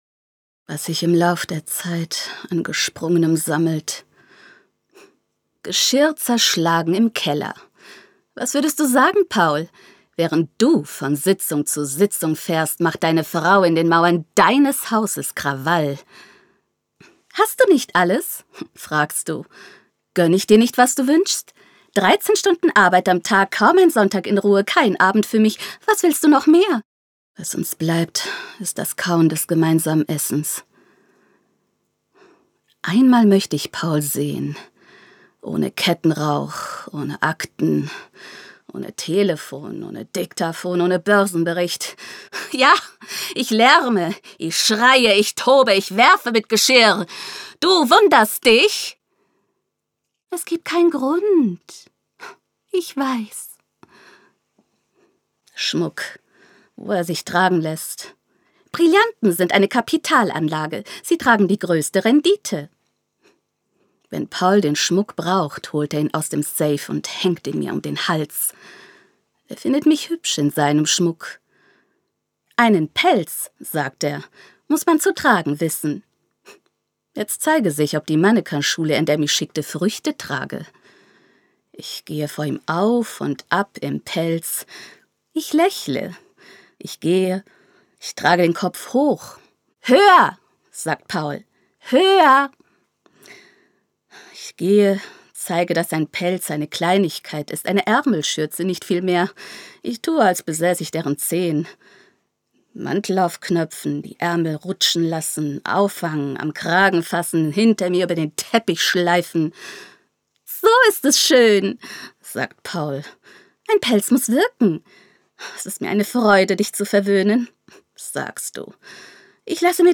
Sprecherin, Synchronsprecherin, Schauspielerin
Programmansage 28.01.2020